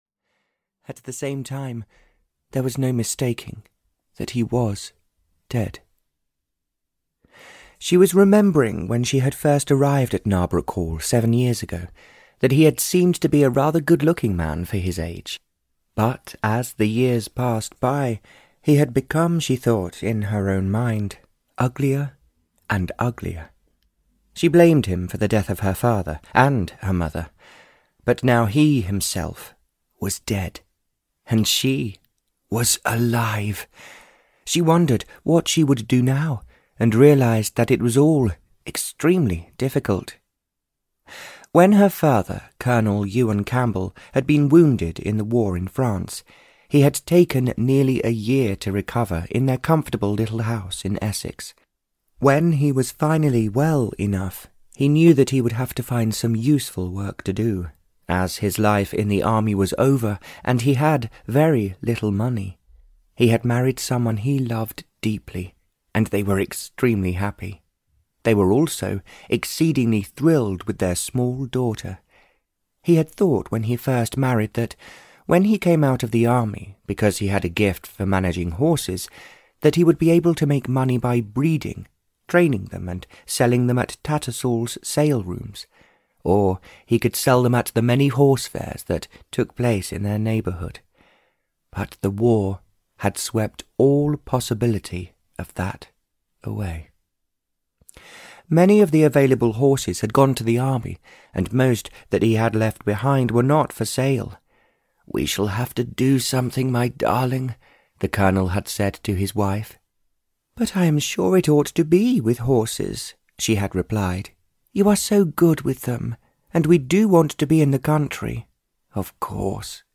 A Golden Lie (EN) audiokniha
Ukázka z knihy